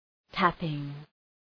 Προφορά
{‘tæpıŋ}